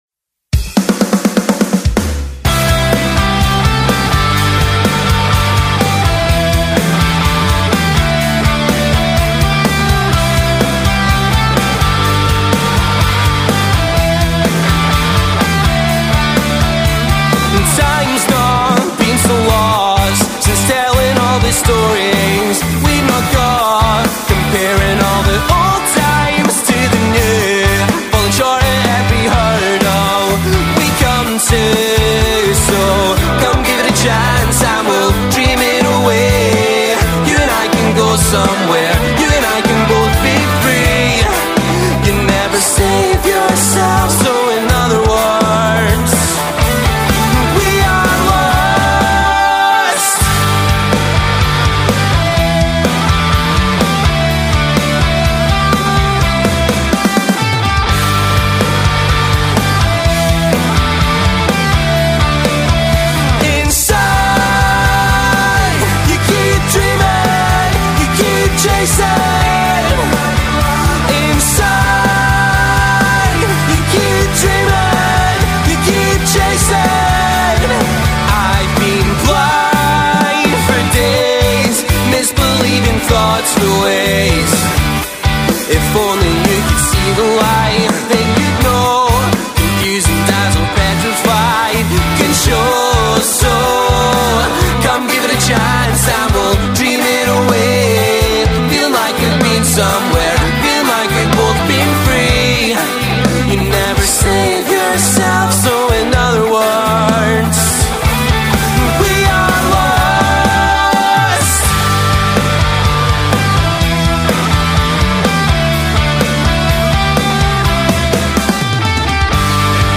pop-rock four-piece